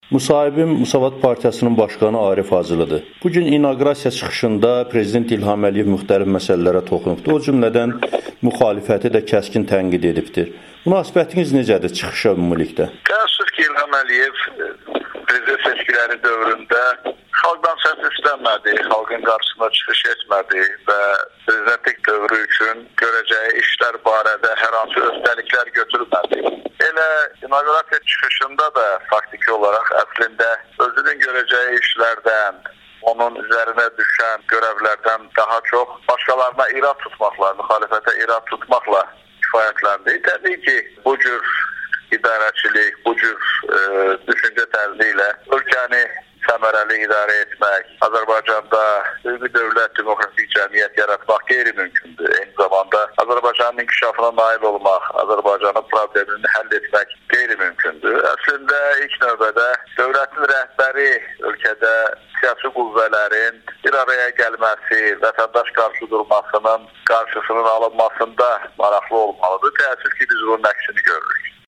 İlham Əliyevin andiçmə çıxışı - müxtəlif münasibətlər [Audio-müsahibələr]
Müsavat Partiyasının başqanı Arif Hacılının Amerikanın Səsinə müsahibəsi